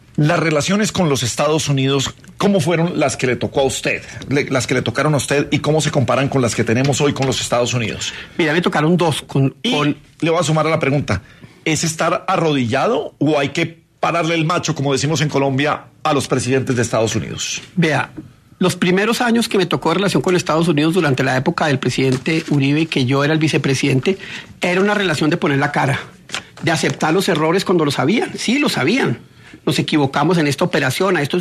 El exvicepresidente Francisco “Pacho” Santos estuvo este miércoles 21 de enero en los micrófonos de Caracol Radio, en el programa La Luciérnaga, donde habló sobre su experiencia en el manejo de las relaciones entre Colombia y Estados Unidos.